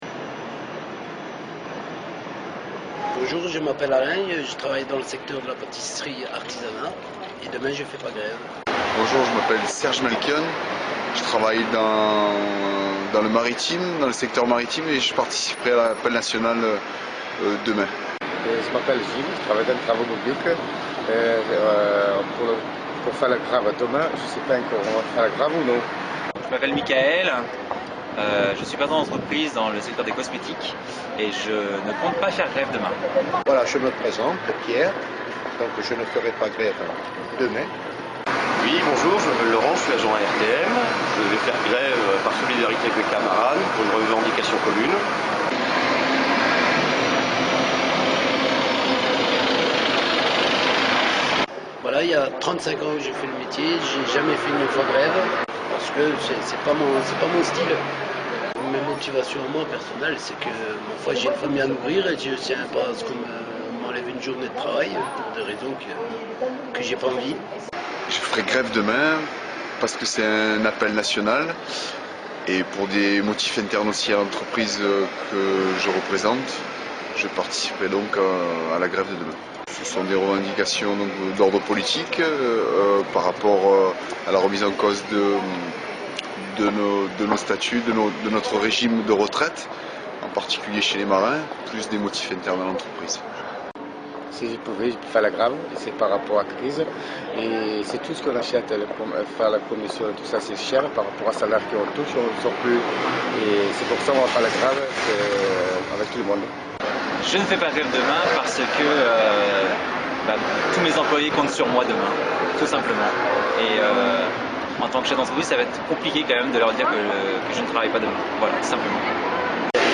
Transcription d'un reportage sur des travailleurs qui s'expriment sur la grève.